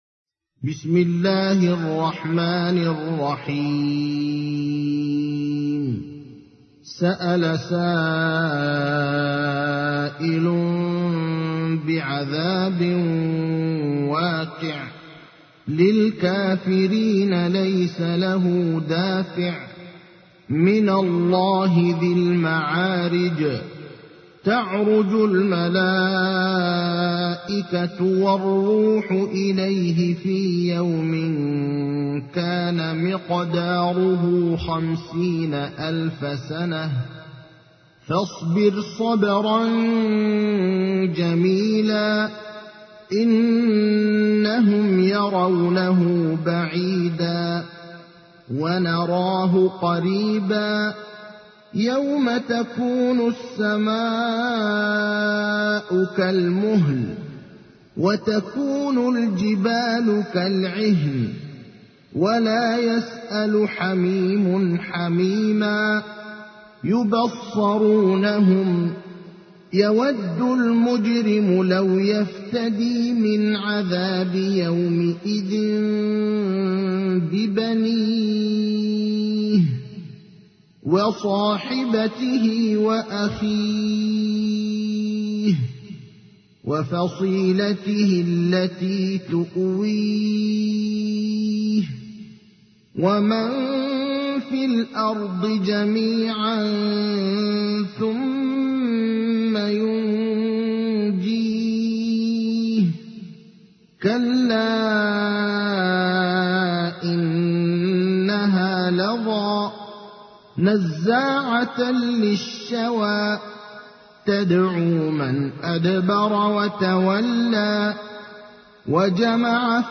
تحميل : 70. سورة المعارج / القارئ ابراهيم الأخضر / القرآن الكريم / موقع يا حسين